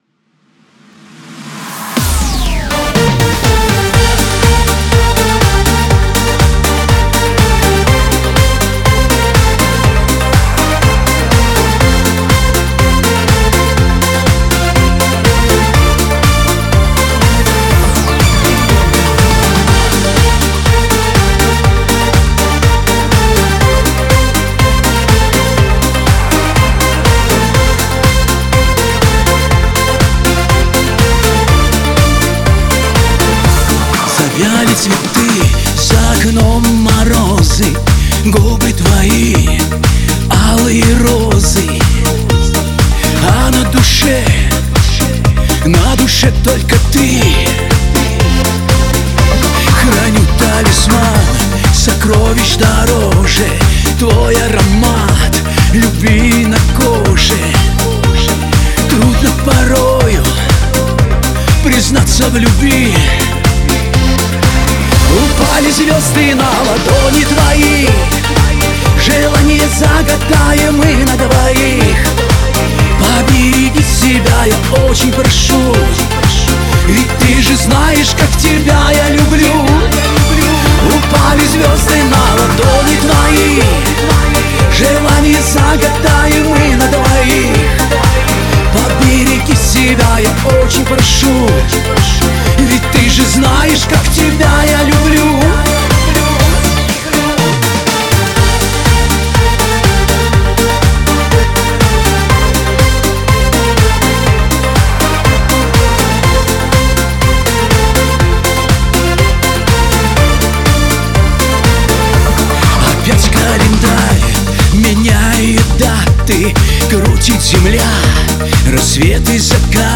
это романтическая и мелодичная песня в жанре поп